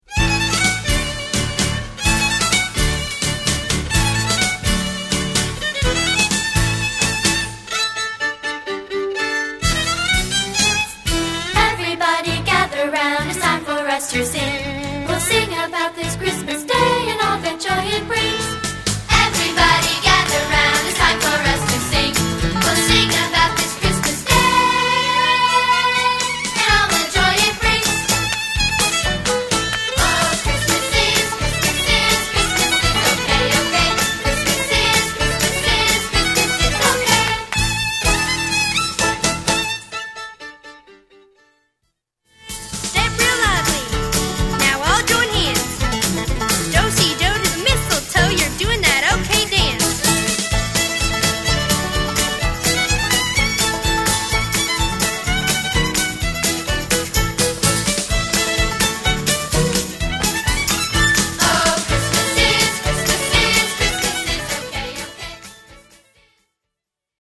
For Young Voices
• and a joyous hoedown finale